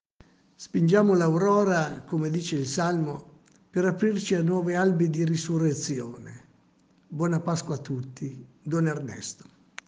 Audio auguri: